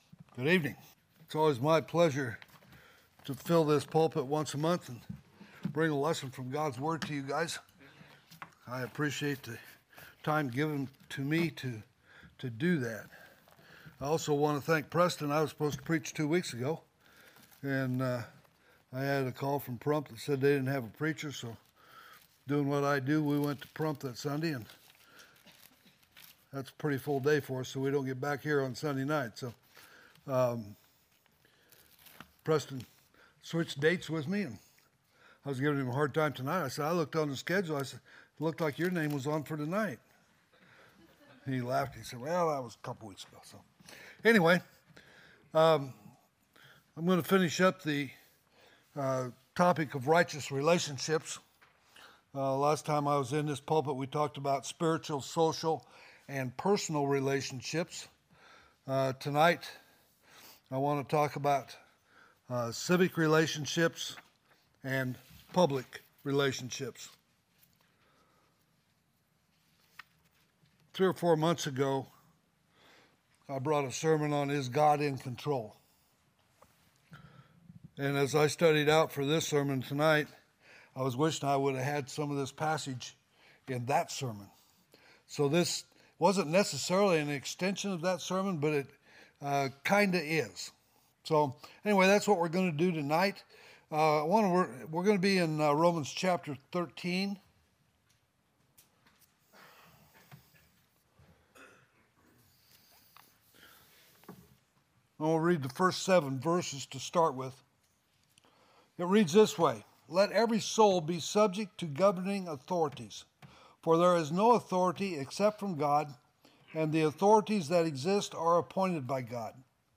2024 (PM Worship) "Righteous Relationships"